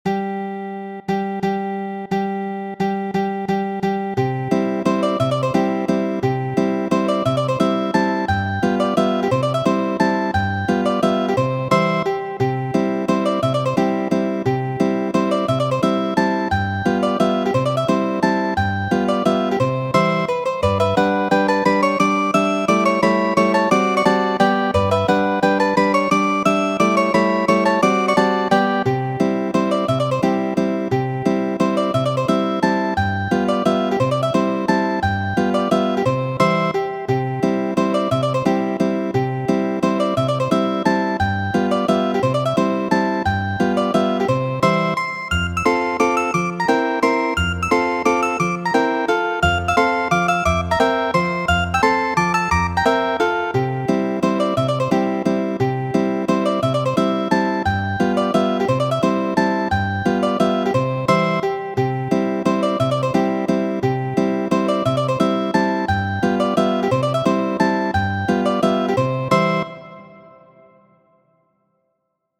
Muziko:
Mazurca, de Federiko Ĉopin'.